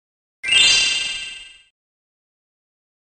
gem-sound-hd_UrJolU2.mp3